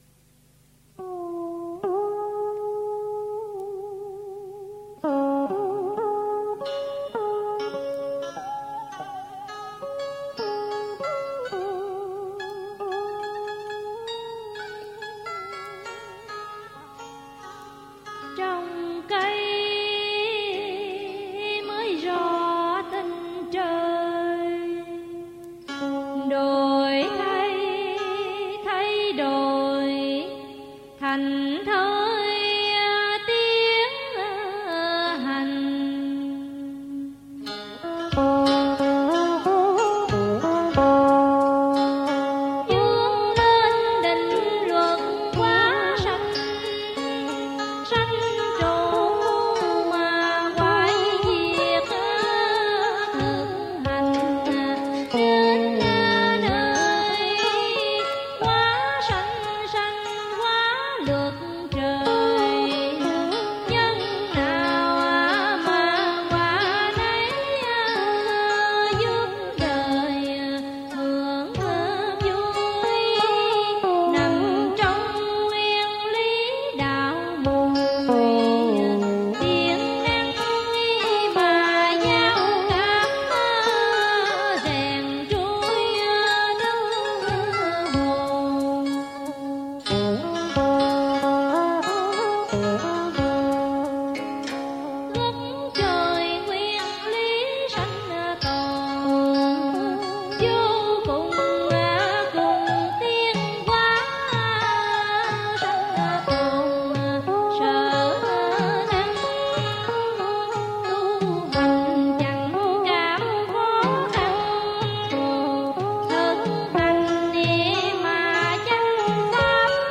Dân Ca & Cải Lương
theo điệu nói thơ Bặc Liêu